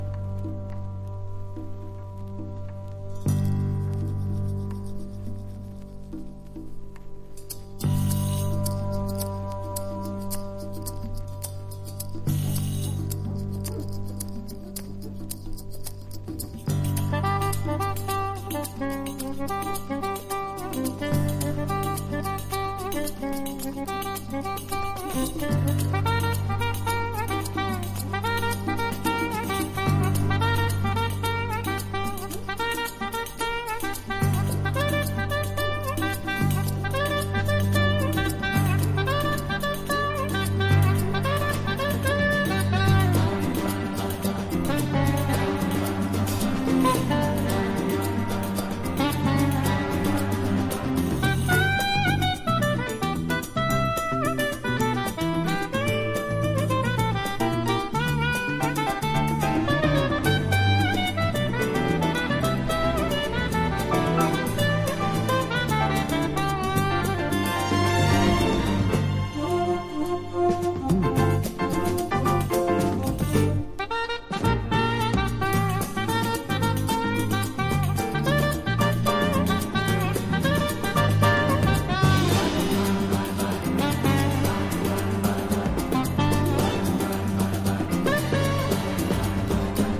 # FUSION / JAZZ ROCK